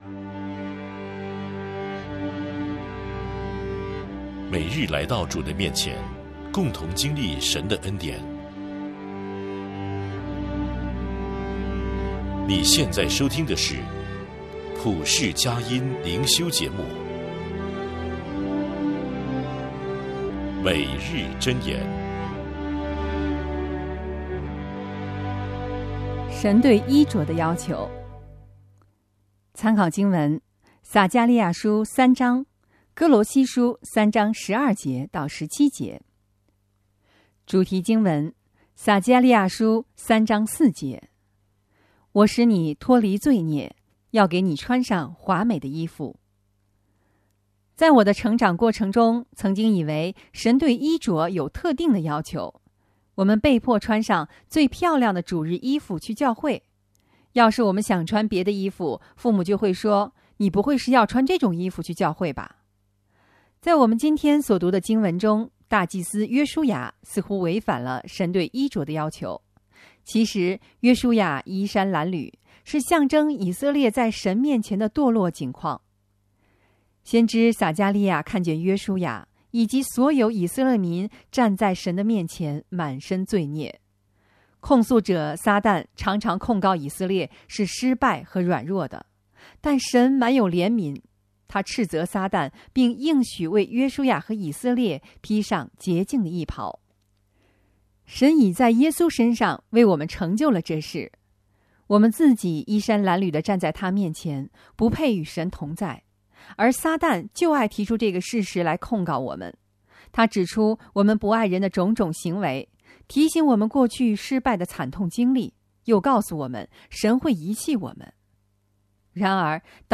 祷告